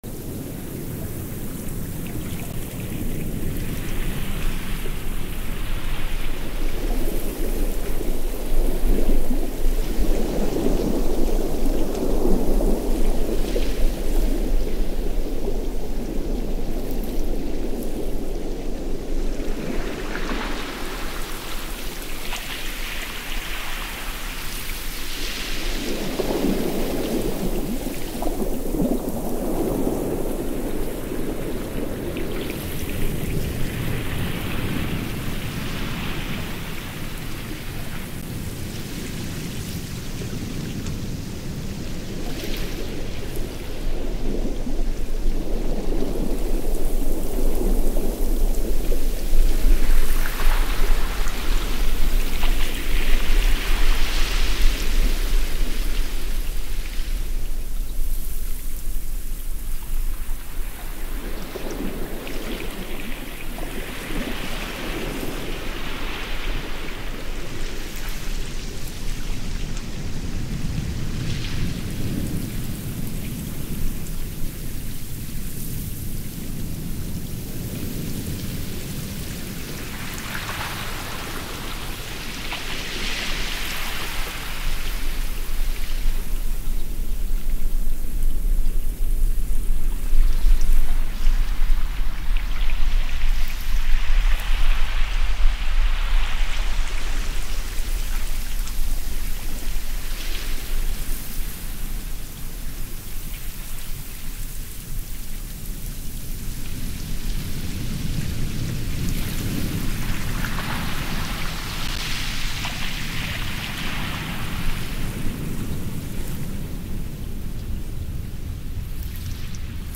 Sonidos-Del-Mar-Para-Niños.mp3